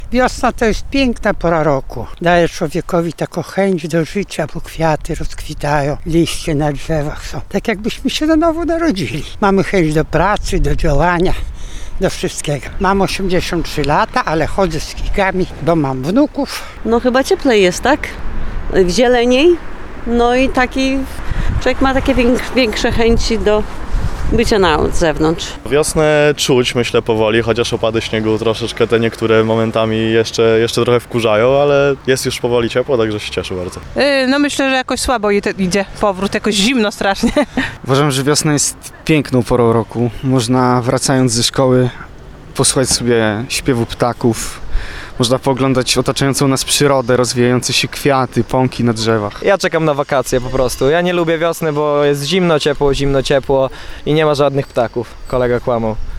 A jak wiosna wpływa na mieszkańców Ełku? Sprawdziliśmy to z mikrofonem na ulicach miasta.